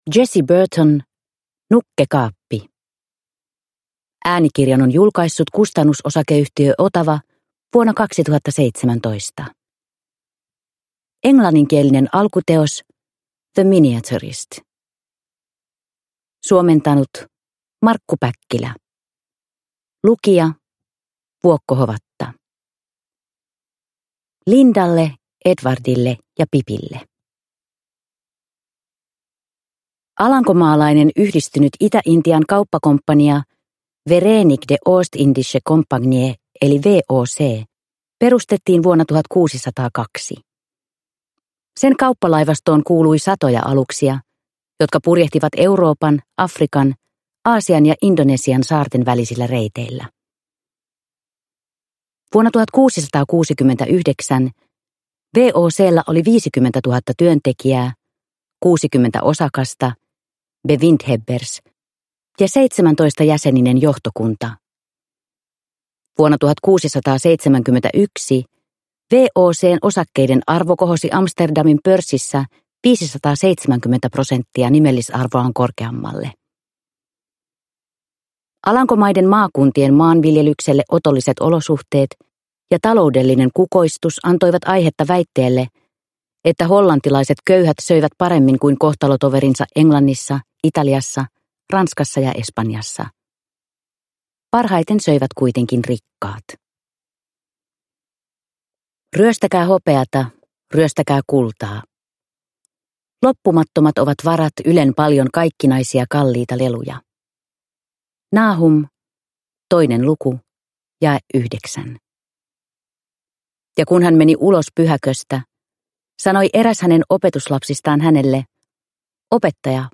Nukkekaappi – Ljudbok – Laddas ner